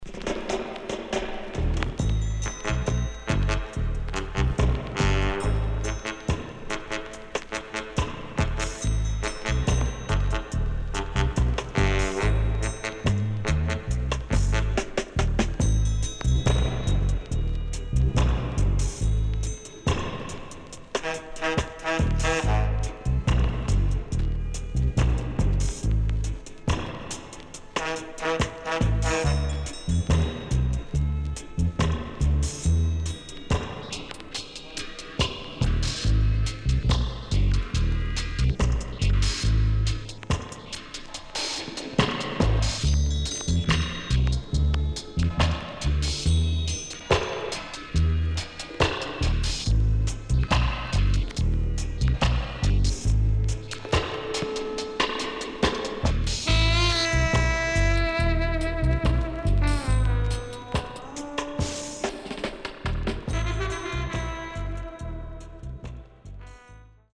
SIDE B:VG(OK)
Horn Inst & Dubwise
SIDE B:所々チリノイズがあり、少しプチノイズ入ります。